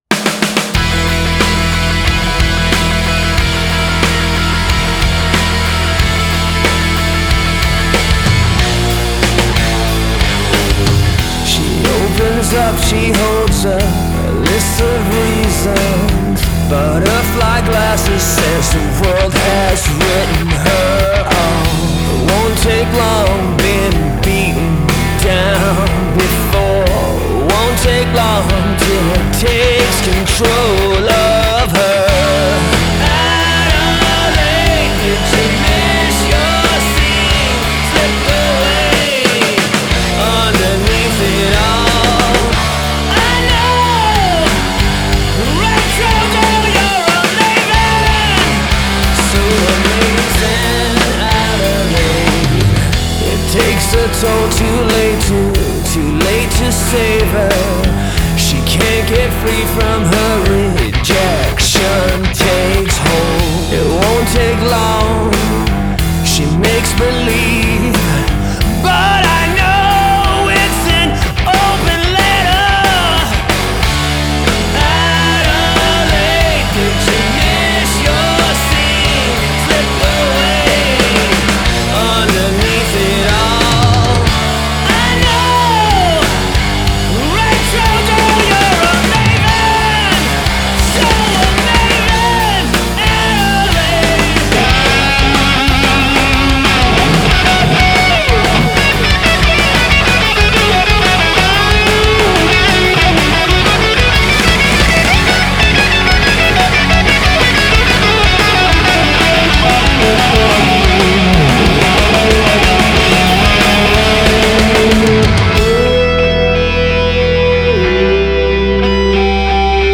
Alternative Rock (Full Band)